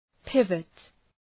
{‘pıvət}
pivot.mp3